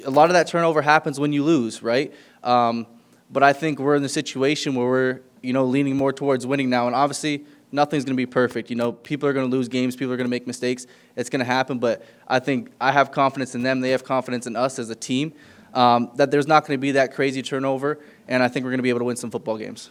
Ford spoke to media about the revolving door of ownership and coaches, stating this new group of management seems to have a common goal of winning.